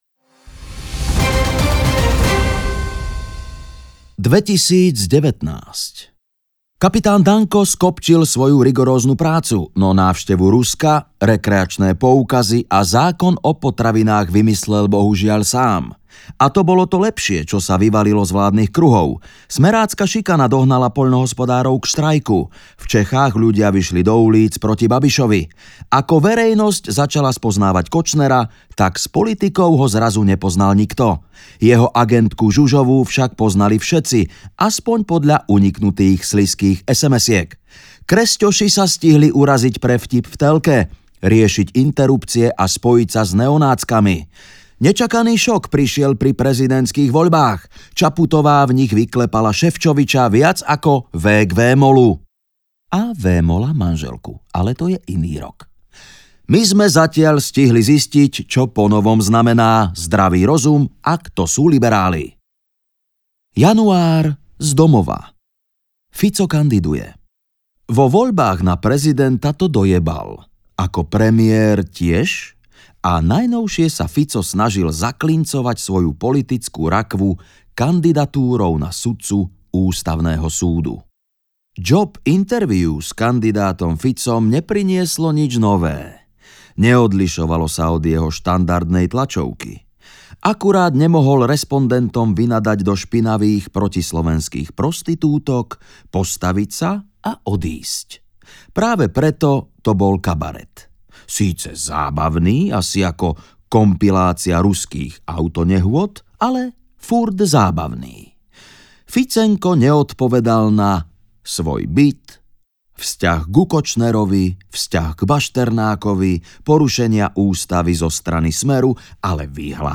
Ukázka z knihy
Pretože autora humor rozhodne neopúšťa ani v temných časoch a interpretácia plná srdu i komičnosti od Juraja Kemku ho ešte umocňuje.
• InterpretJuraj Kemka